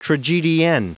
Prononciation du mot tragedienne en anglais (fichier audio)
Prononciation du mot : tragedienne